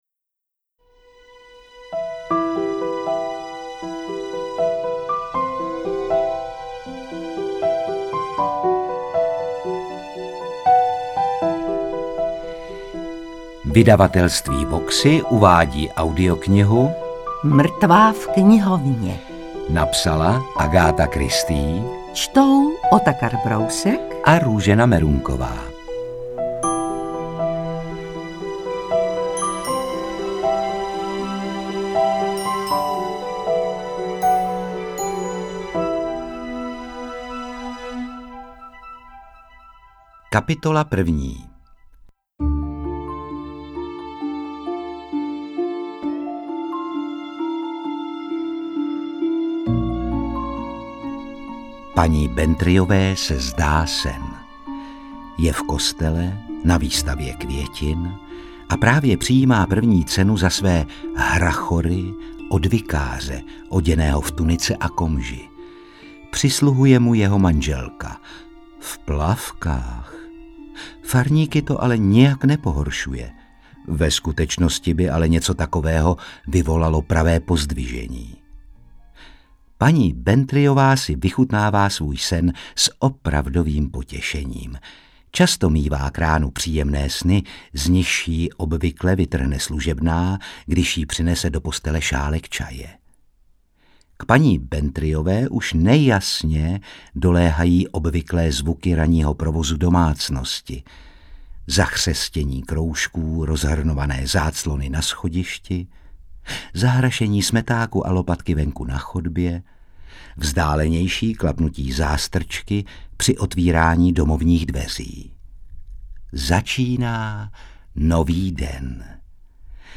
Interpreti:  Otakar Brousek, Růžena Merunková